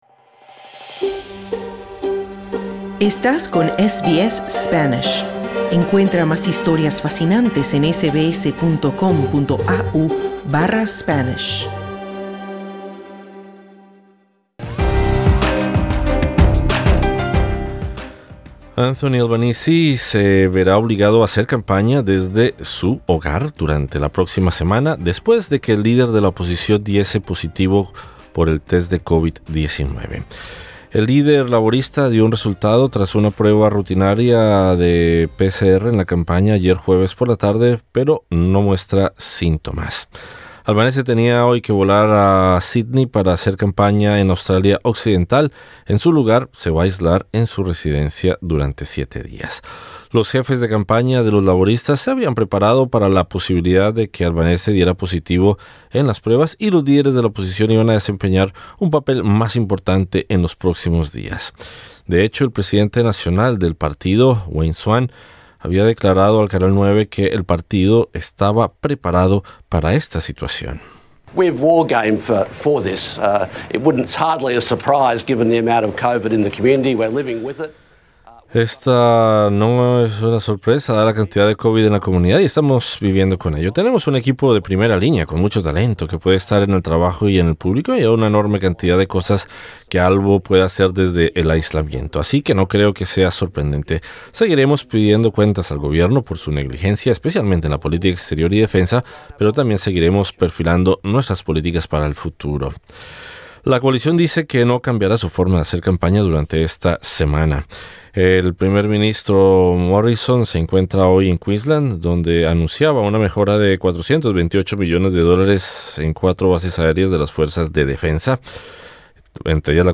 Entrevistado: Telmo Laguiller ex diputado laborista y ex presidente del parlamento de Victoria.